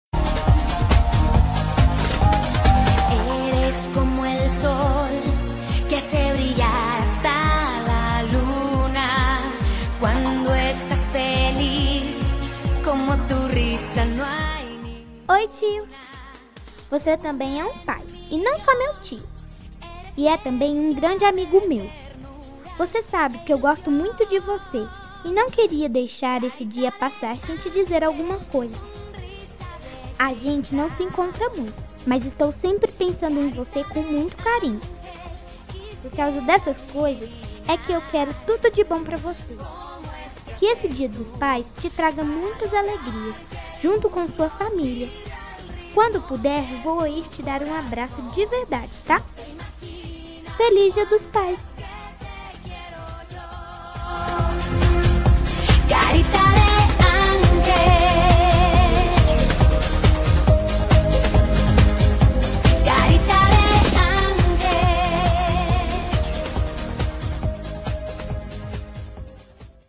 Voz de Criança